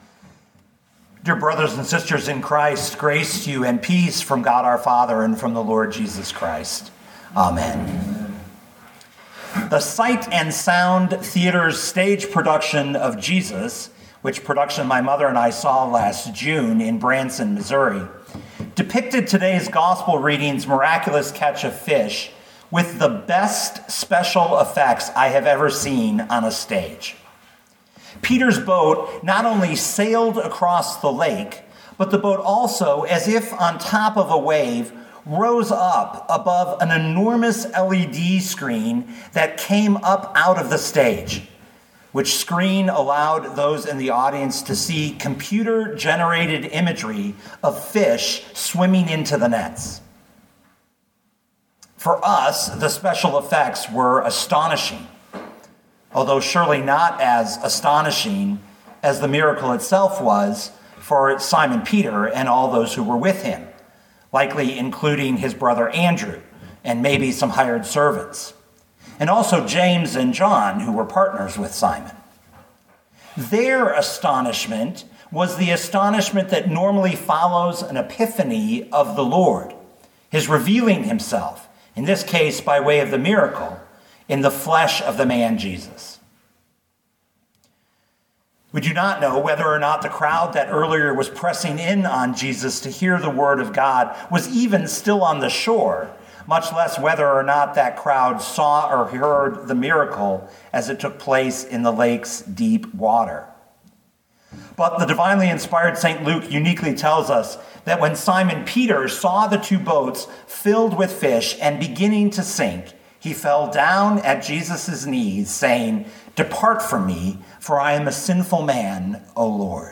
2022 Luke 5:1-11 Listen to the sermon with the player below, or, download the audio.